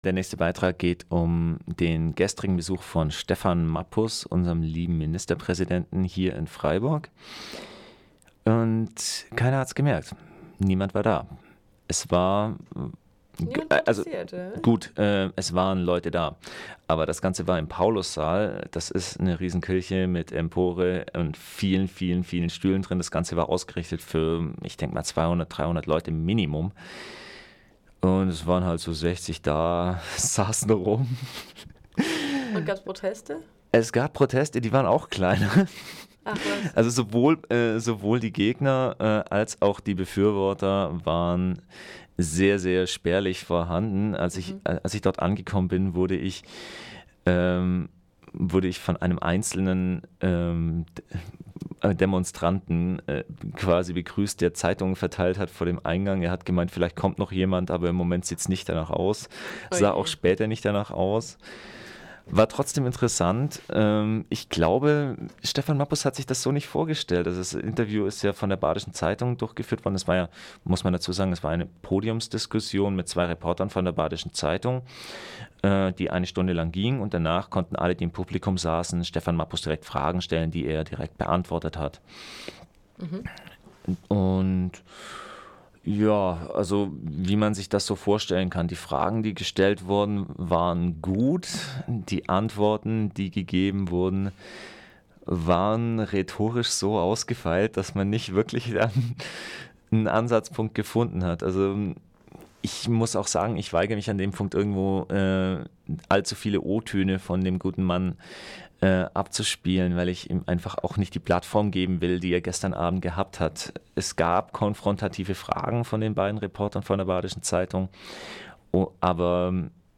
Am 13. Dezember war Ministerpräsident Mappus zum öffentlichen Gespräch mit Reportern der badischen Zeitung im Paulussaal, Dreisamstr. 3 in Freiburg.